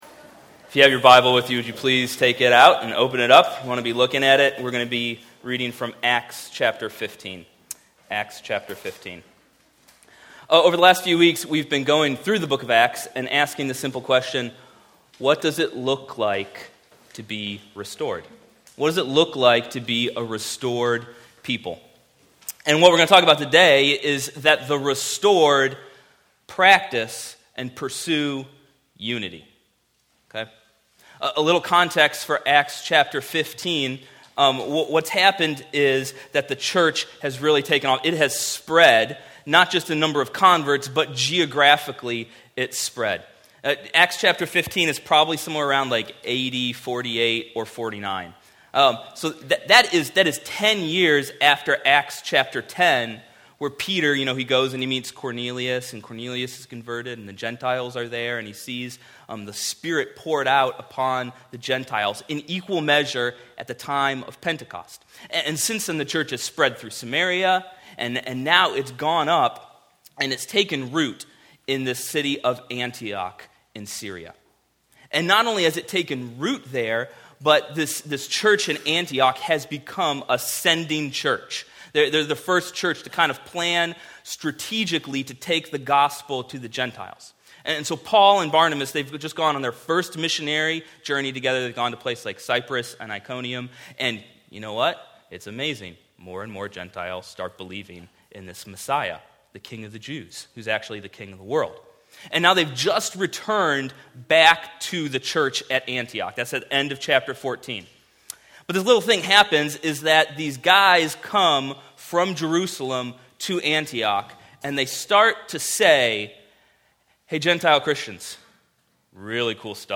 Passage: Acts 15:1-35 Service Type: Weekly Sunday